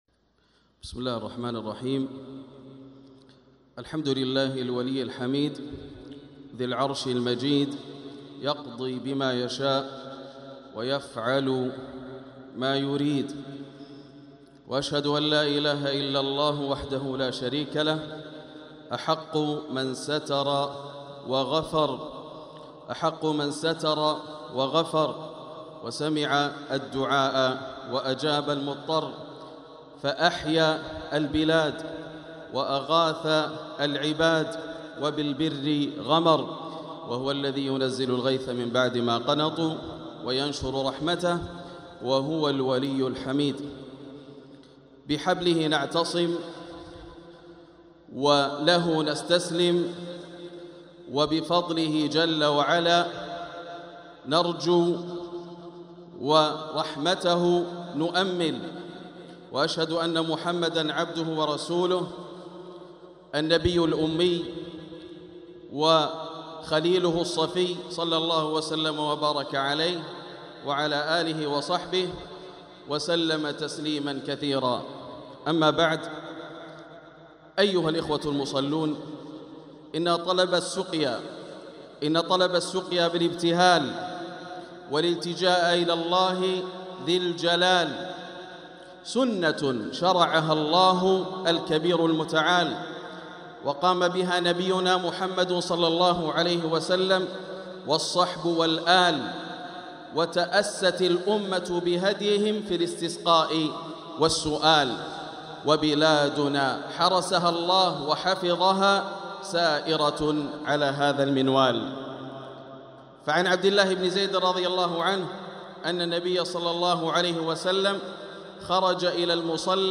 كلمة تذكيرية للشيخ ياسر الدوسري للتذكير بصلاة الاستسقاء بعد صلاة العشاء 25 جمادى الأولى 1446هـ  > تلاوات - كلمات أئمة الحرم المكي > تلاوات - كلمات أئمة الحرم المكي 🕋 > المزيد - تلاوات الحرمين